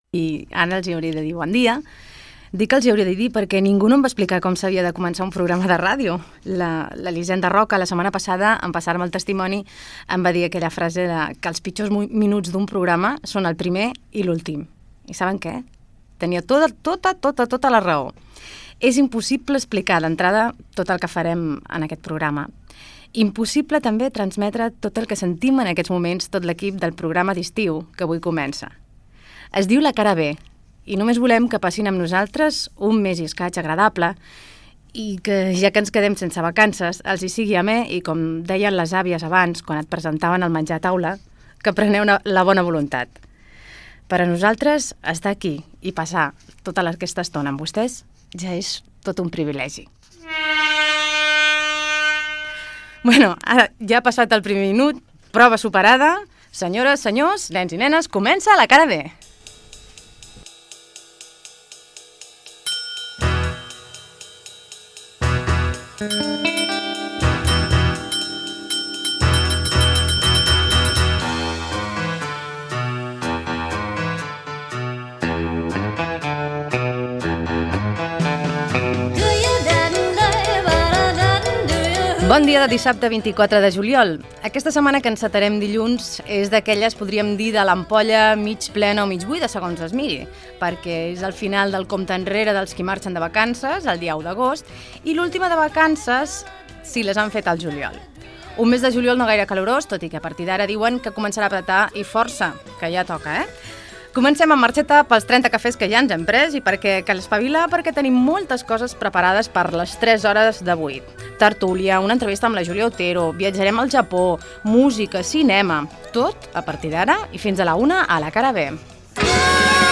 Inici del primer programa, presentació, sumari. Fragment d'una entrevista telefònica a la presentadora Elisenda Roca.
Entreteniment